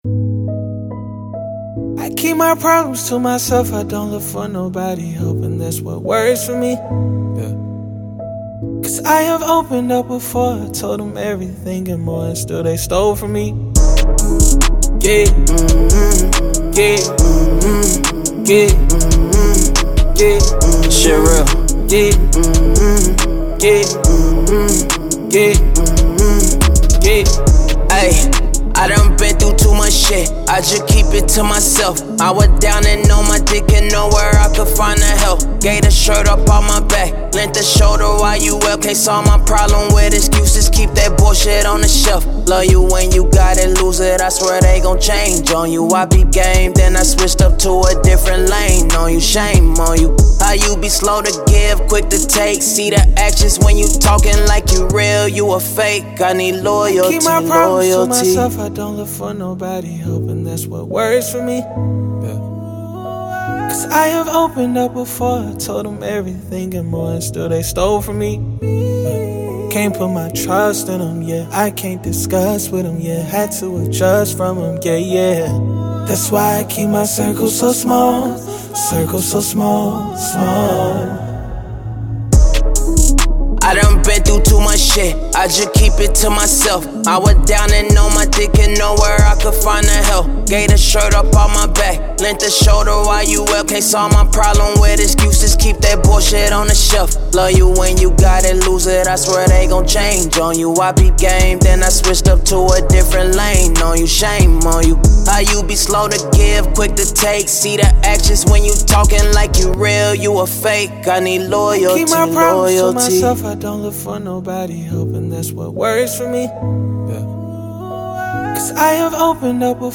Hip Hop
C# min